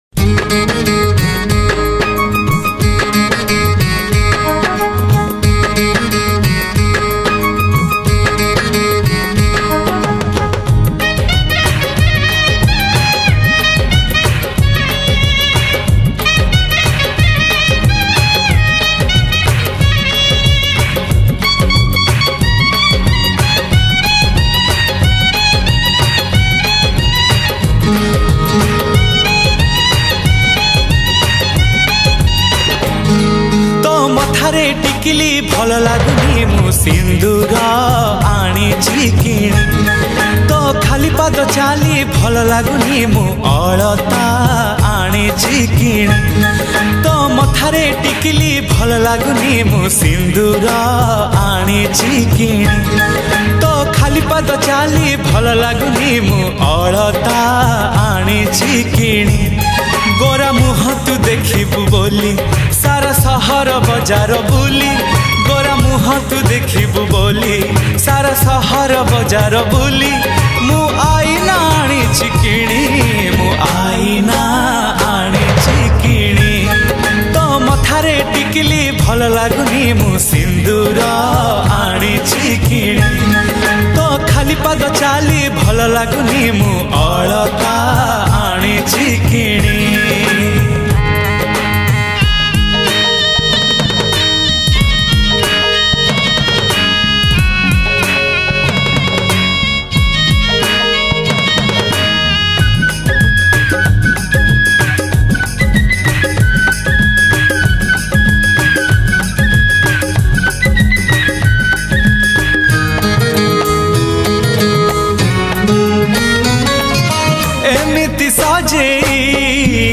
Odia Romantic Cover Version